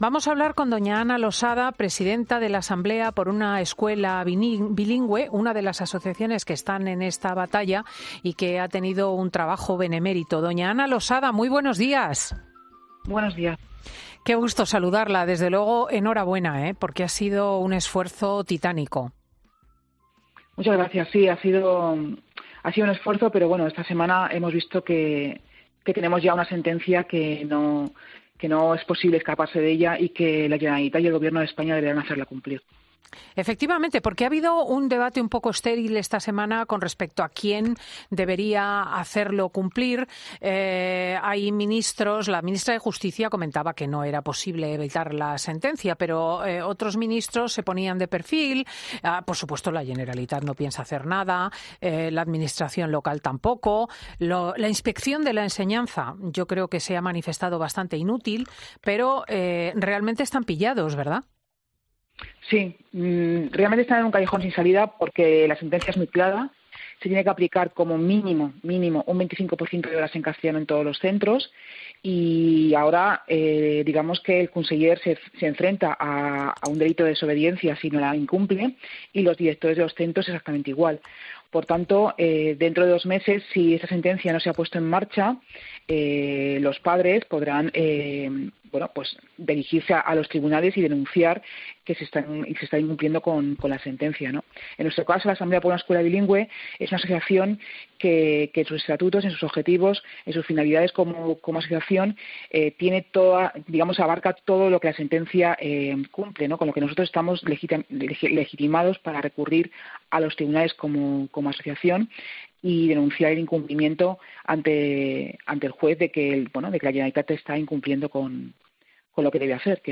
Lo ha hecho en los micrófonos de Fin de Semana de COPE, donde celebraba el rechazo del TS al recurso de la Generalitat y destacaba que tanto el gobierno catalán como el Gobierno de España lo deben hacer cumplir.